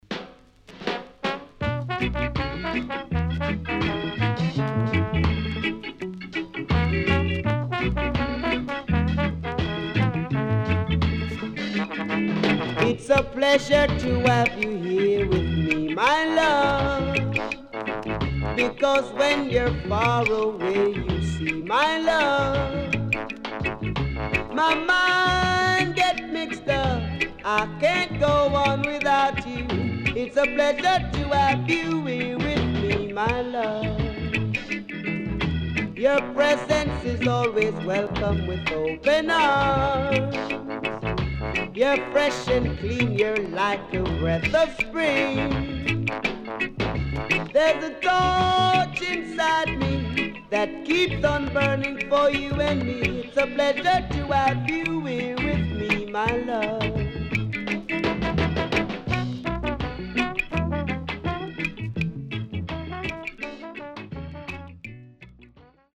HOME > REGGAE / ROOTS  >  定番70’s
Side A:少しノイズ入りますが良好です。